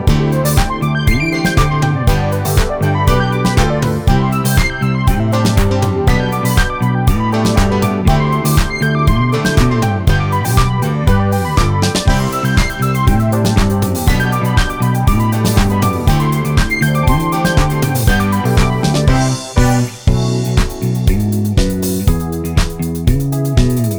no Backing Vocals Soundtracks 3:29 Buy £1.50